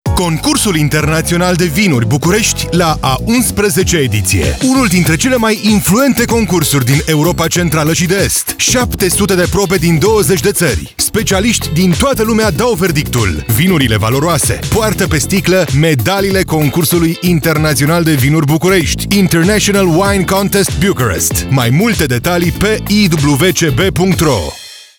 Click below to listen to the radio spot (in Romanian)